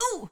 SFX_Battle_Vesna_Defense_01.wav